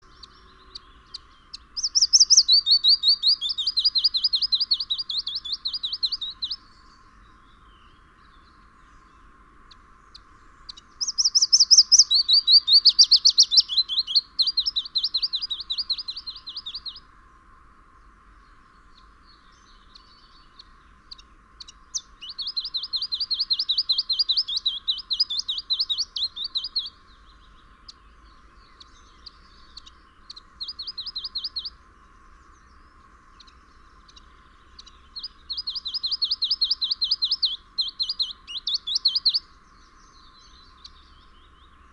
Sekalaulava tiltaltti / A song switching Common Chiffchaff (Phylloscopus collybita)
Most of the strophes we heard were Willow Warbler - like, although somewhat staggering. There were often Chiffchaff-like quiet notes between the strophes.